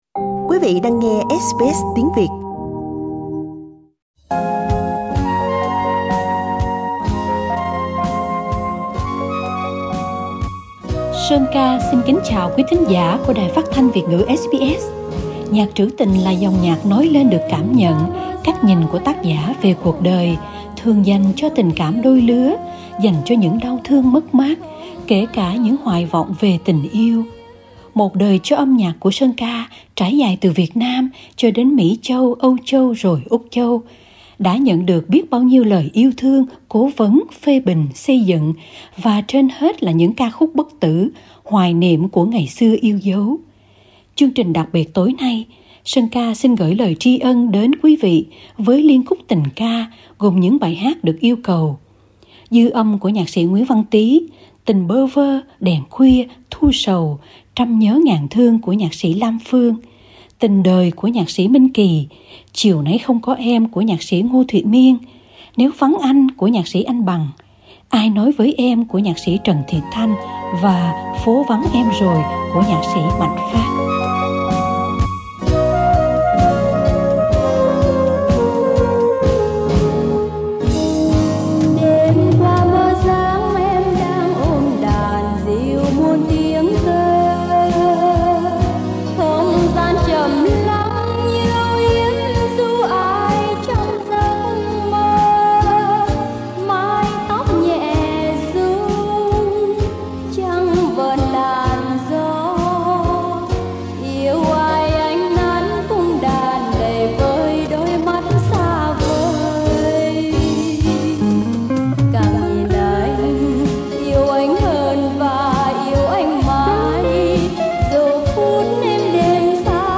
Hai anh em cùng song ca liên khúc được nhiều khán thính giả khắp nơi yêu cầu khi đi lưu diễn.